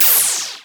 ihob/Assets/Extensions/RetroGamesSoundFX/Shoot/Shoot09.wav at master
Shoot09.wav